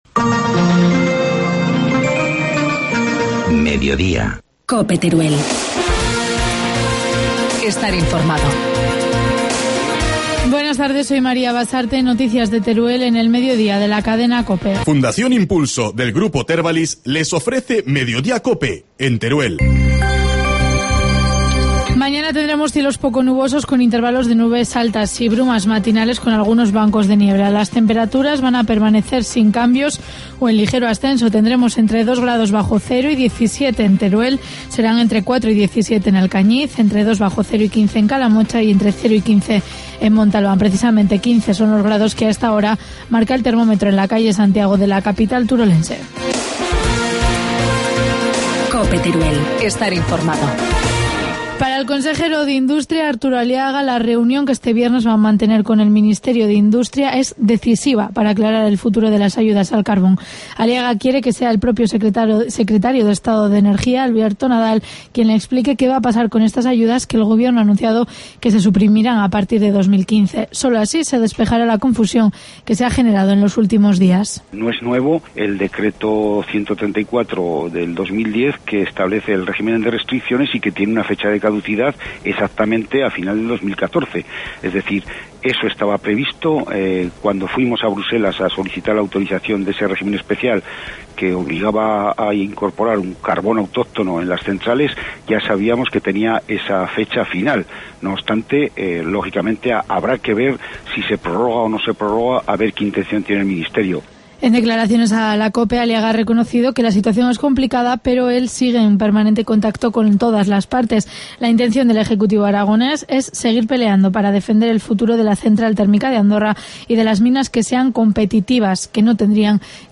Informativo mediodía, miércoles 30 de enero